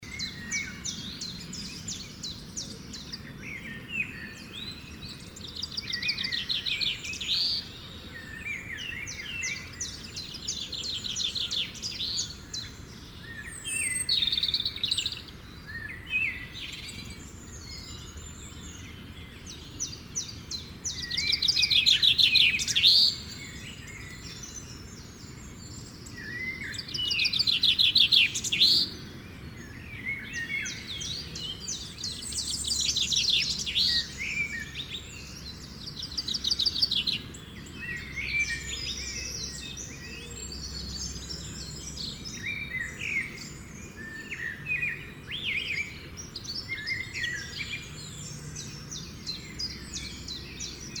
Nature Sounds
Chant des Oiseaux
03.-morning_birds.mp3